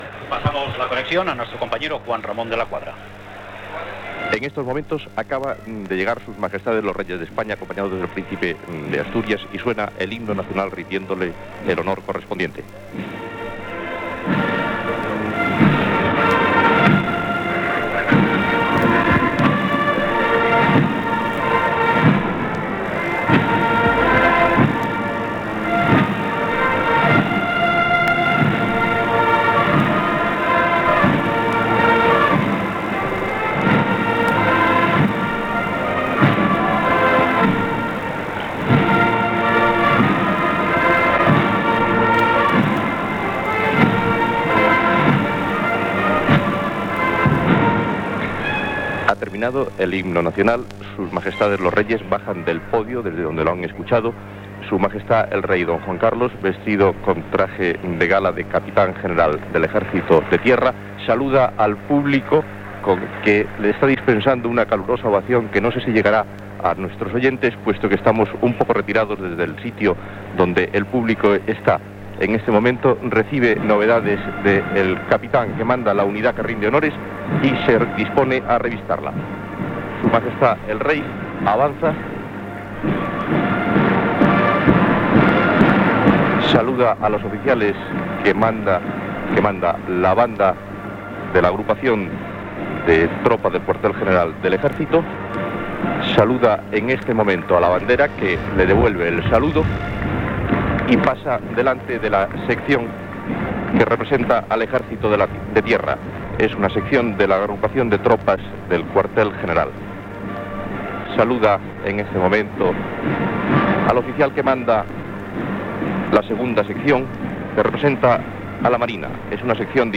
4318e1b484ca24f4e144c3dc0fc9f666dca5e62c.mp3 Títol Radio Nacional de España Emissora Radio Nacional de España Barcelona Cadena RNE Titularitat Pública estatal Descripció Transmissió des del Palacio de las Cortes de Madrid de l'acte de la sanció de la Constitució Espanyola en una sessió conjunta del Congrés dels Diputats i el Senat.